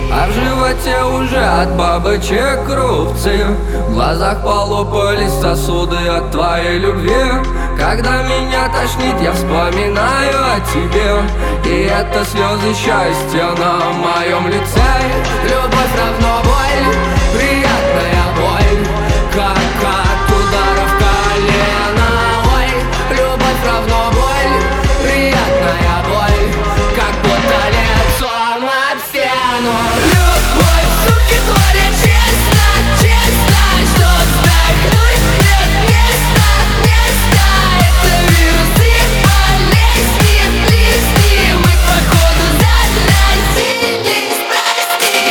• Качество: 320, Stereo
грустные
alternative
мрачные